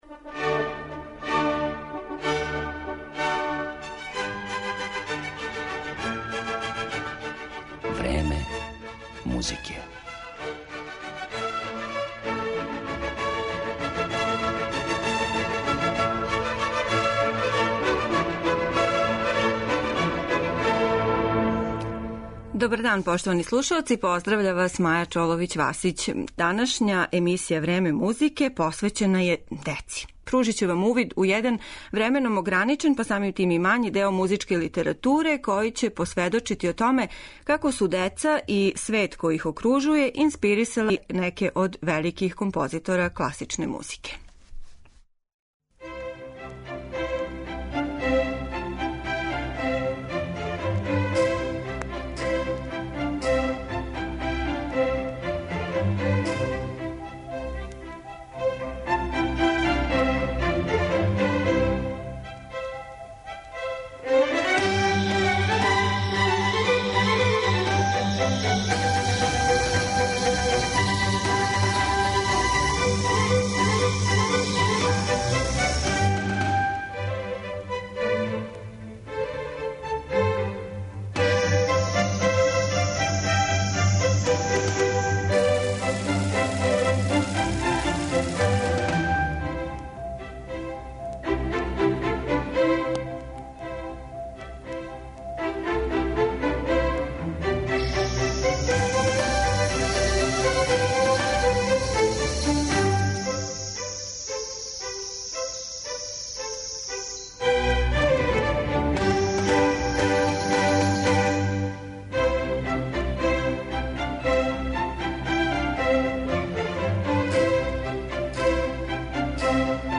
Слушаћете фрагменте из композиција Шумана, Дебисија, Мусоргског, Равела, Прокофјева и других.